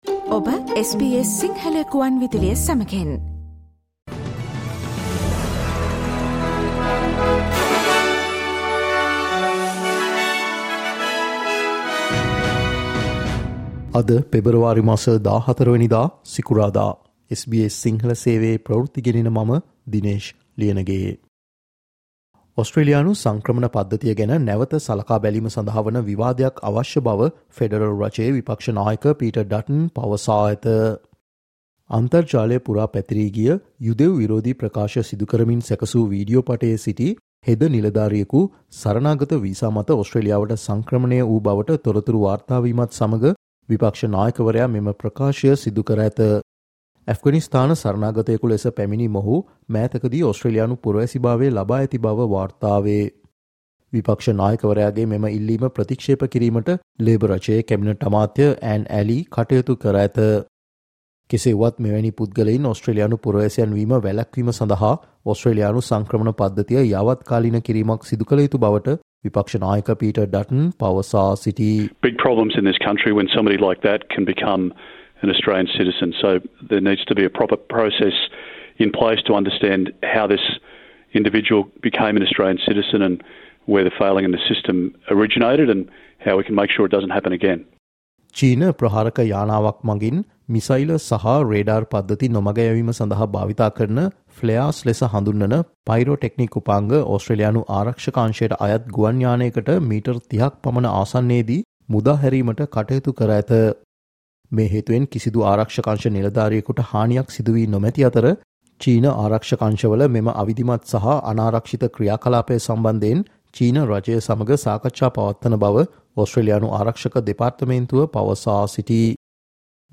SBS Sinhala Newsflash 14 Feb: Opposition Leader has called for a debate on Australia's migration system